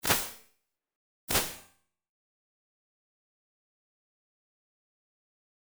вот, тут первый ваш, а второй с плагина, но после такой эквализации
Вложения impulse.mp3 impulse.mp3 227,5 KB · Просмотры: 1.608